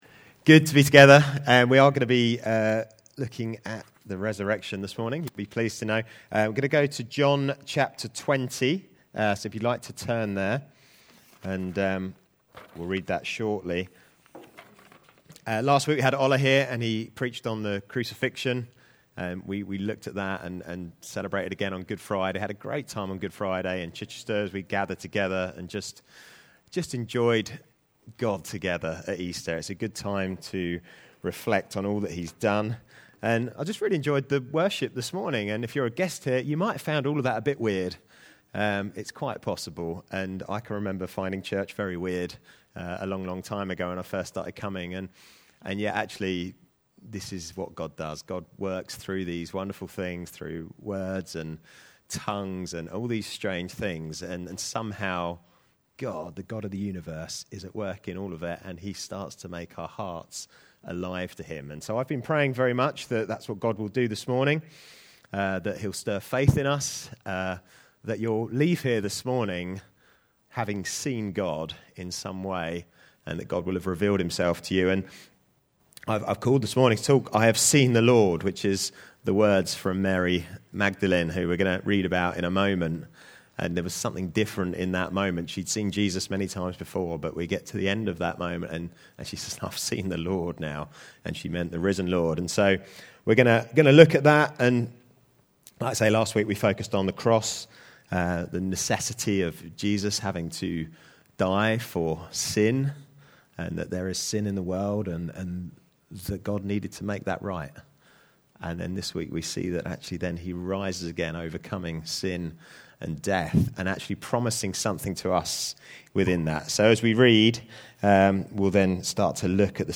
Easter Sunday 2025
Series: Other Sermons 2025